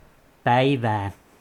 Ääntäminen
IPA: /ˈpæiʋæː/ IPA: /ˈpæivæː/